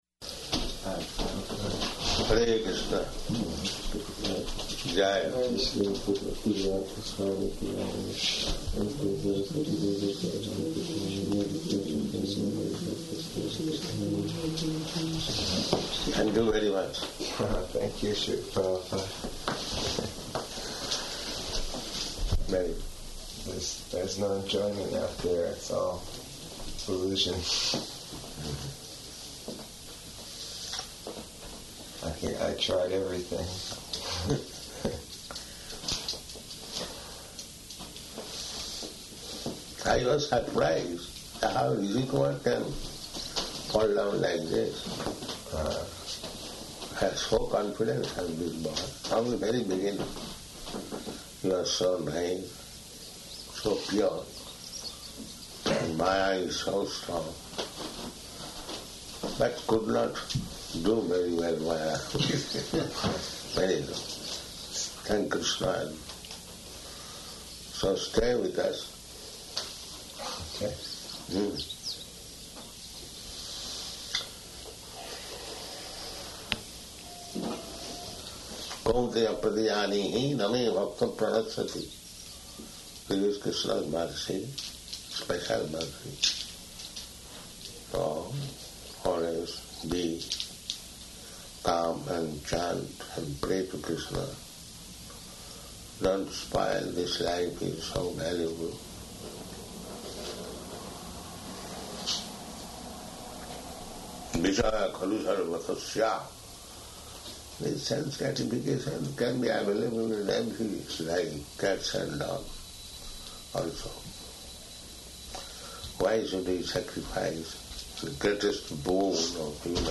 Room Conversation
-- Type: Conversation Dated: June 28th 1976 Location: New Vrindavan Audio file